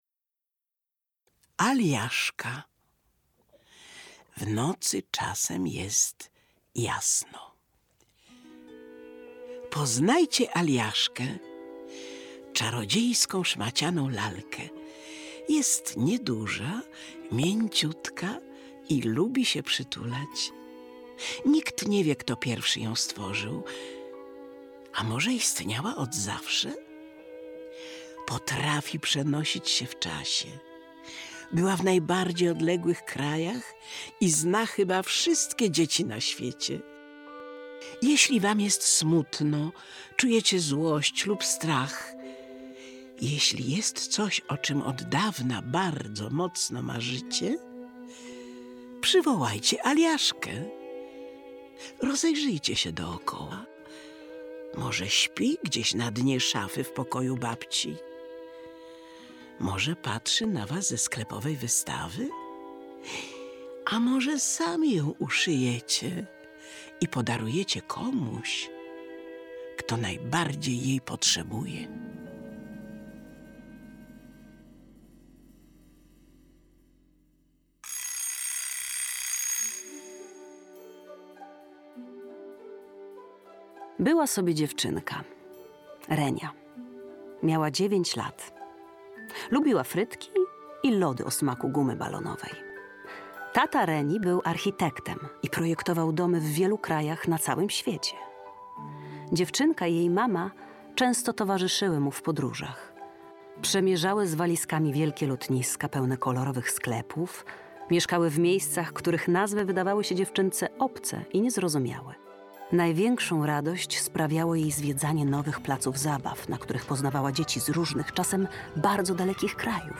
Aliaszka - W nocy czasem jest jasno - Magdalena Górska - audiobook